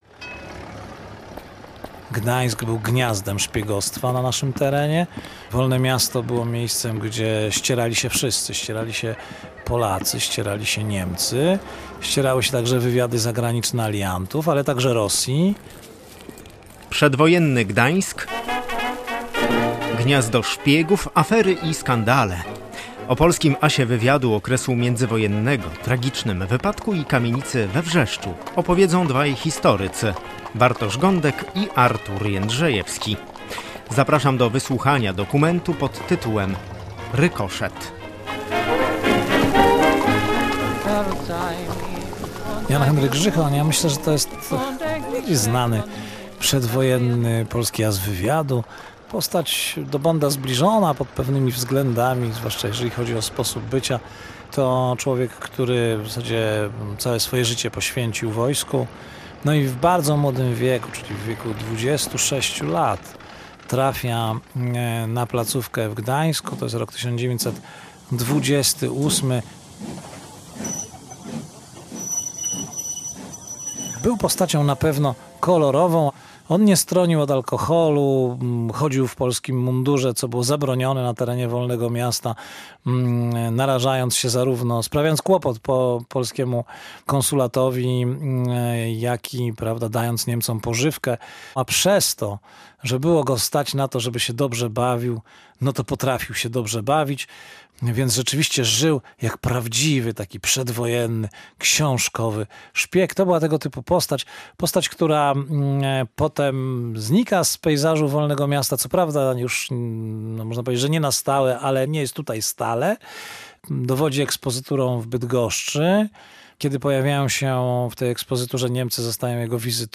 Szpieg w Wolnym Mieście Gdańsku. Posłuchaj dokumentu „Rykoszet”